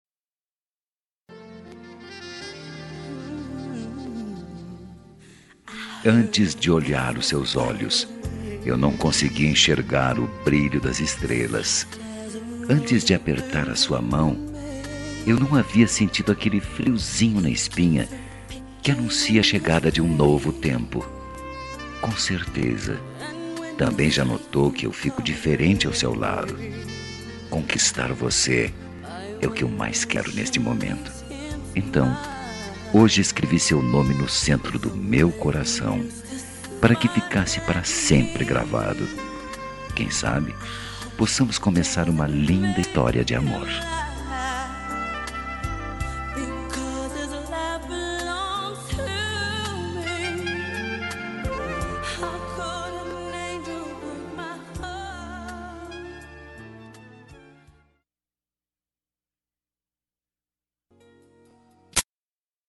Telemensagem de Paquera – Voz Masculina – Cód: 2112